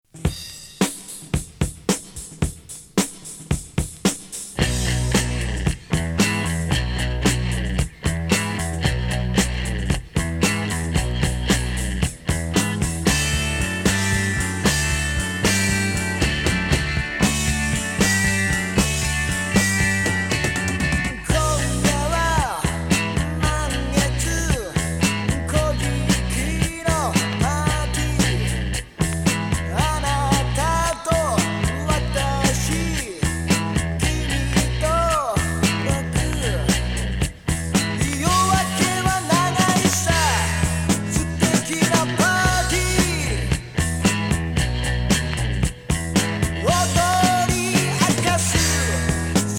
イントロ・ブレイク!!ファンキー和グルーヴ!!